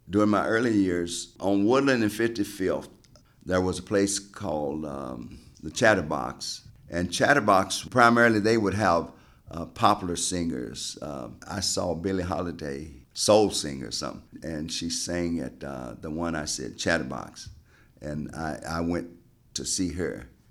Source Cleveland Regional Oral History Collection